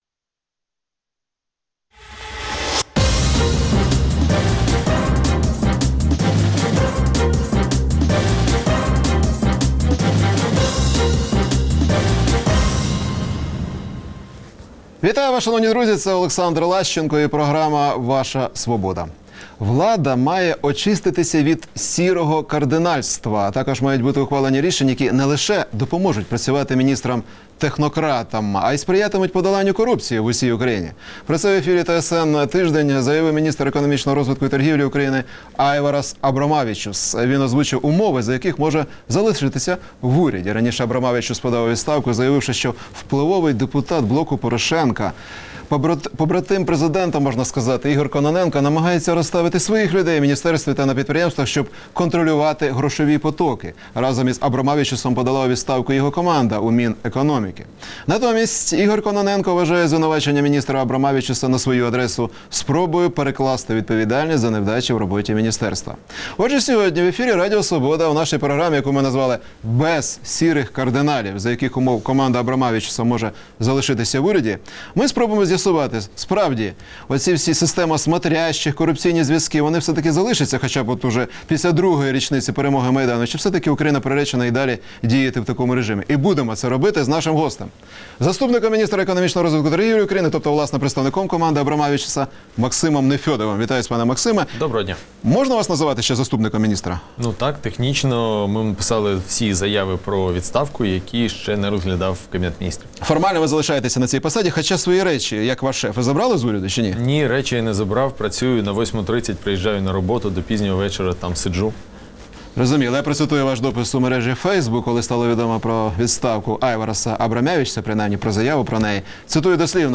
Гість: Максим Нефьодов, заступник міністра економічного розвитку і торгівлі